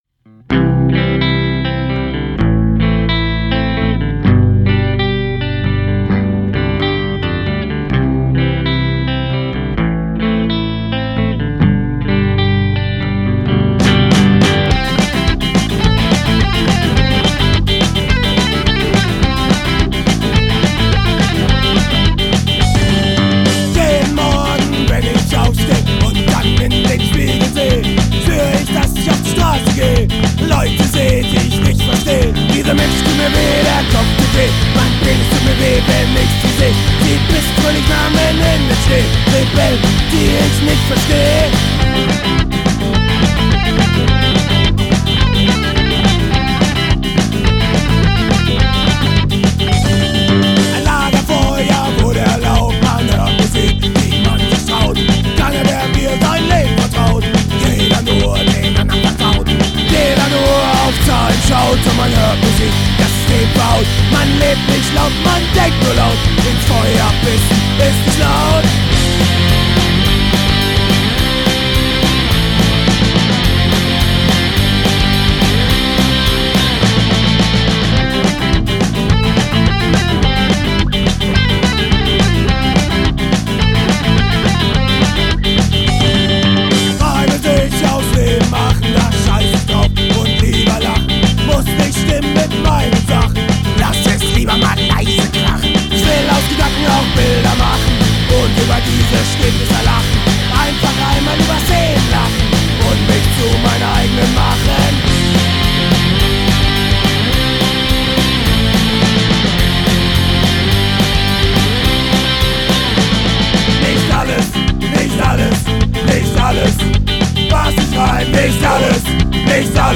Alte Demoaufnahmen: